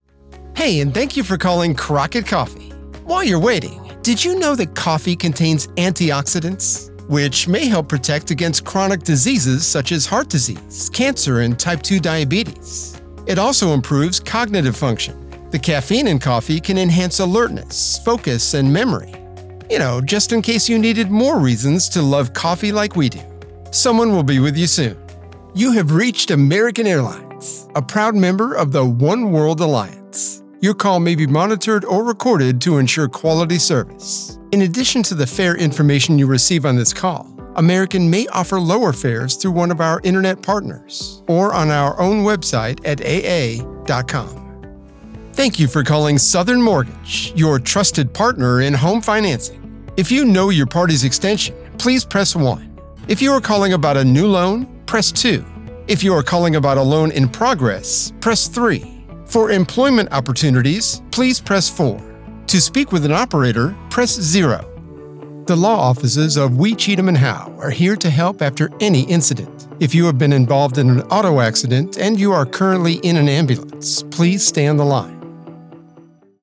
Voice Actors for On-Hold Messages & Voicemail | Discover Now